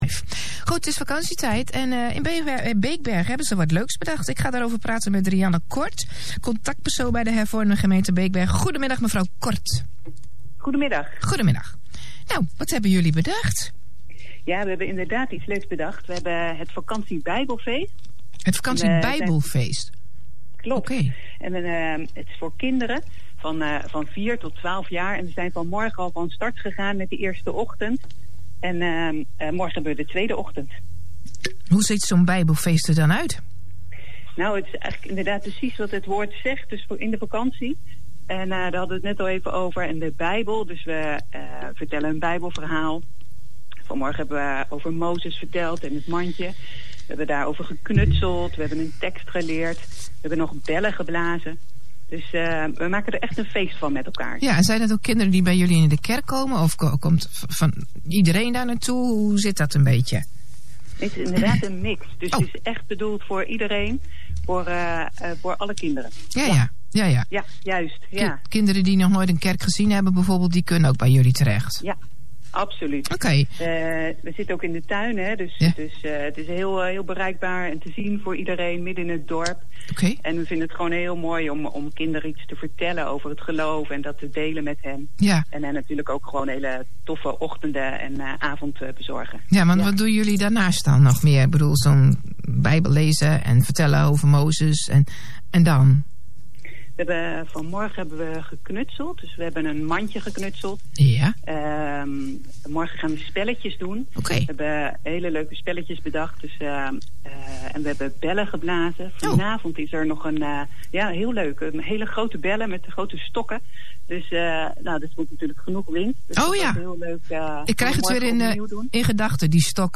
Vanmiddag was ons VBF op de radio te beluisteren via RTV Apeldoorn.